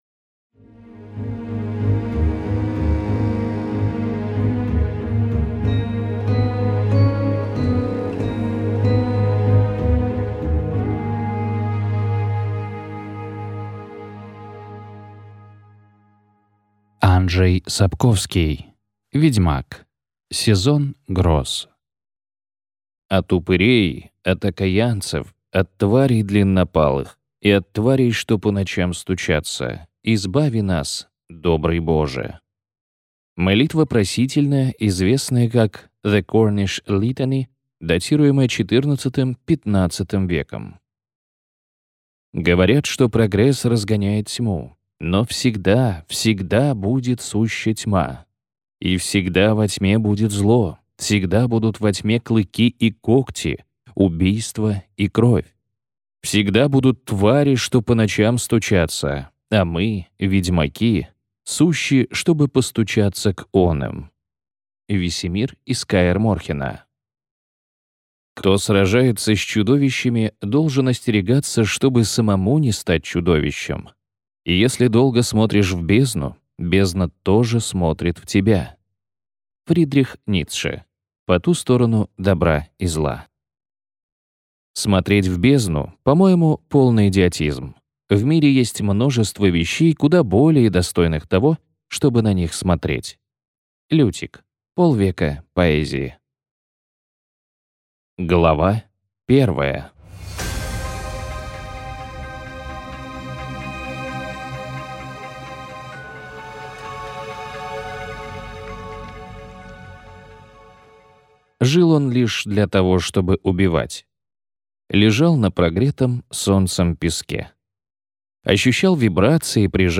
Аудиокнига Сезон гроз - купить, скачать и слушать онлайн | КнигоПоиск